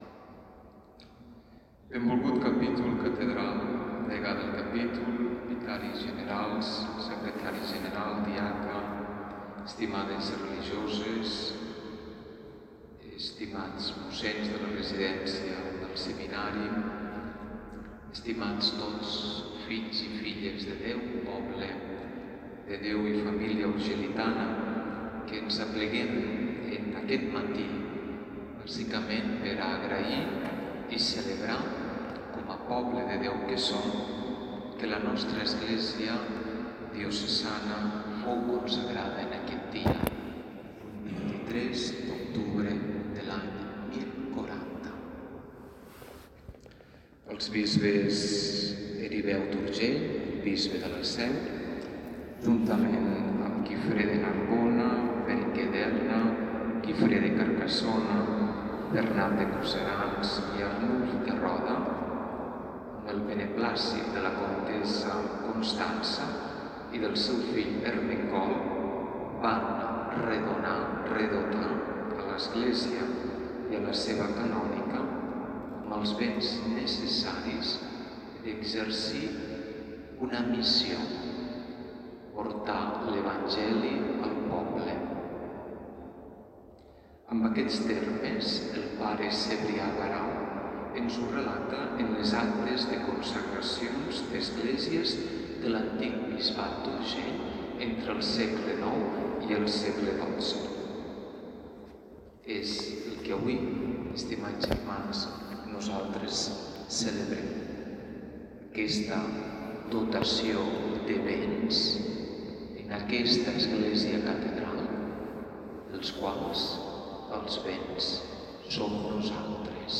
Per reviure la celebració, podeu escoltar l’homilia a continuació:
Homilia-Mons.-Serrano-Festa-de-dedicacio-a-la-Catedral.mp3